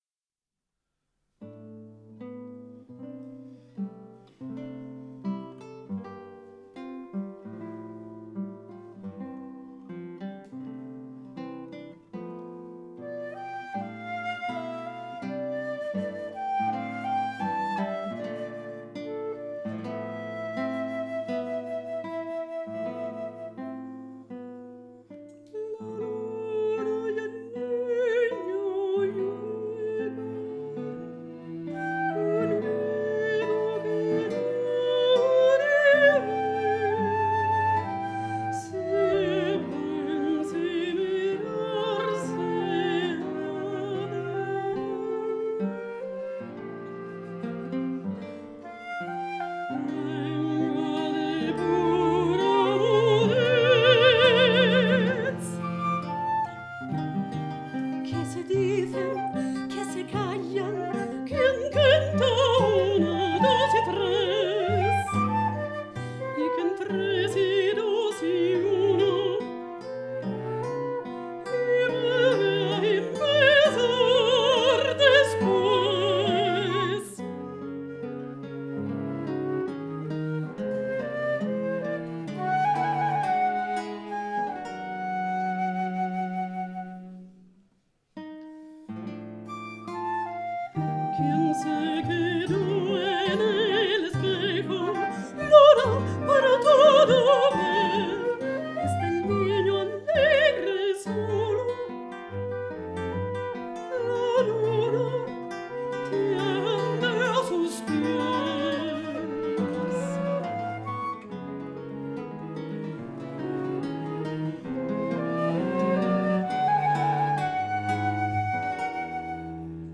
mezzosoprano
flauto
violoncello
chitarra
Archivio Storico della Città di Torino
Live recording, Giugno 2007